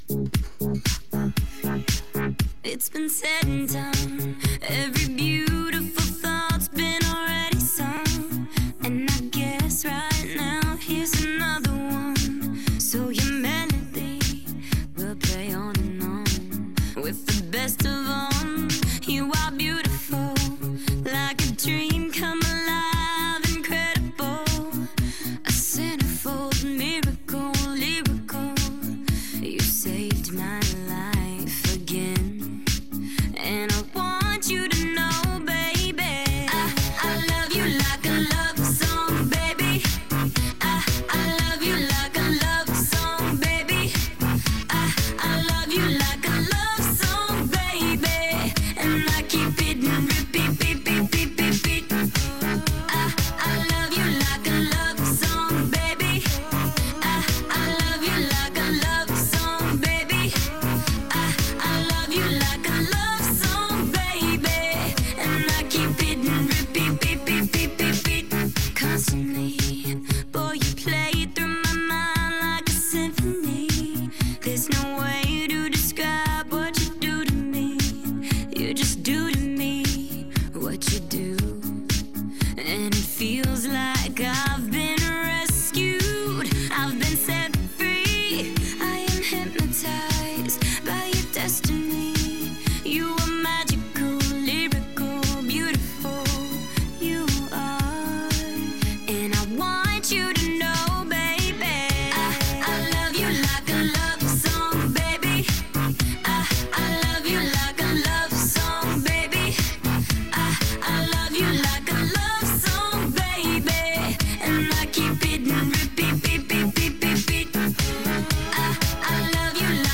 retro mixtape 2011_0930.mp3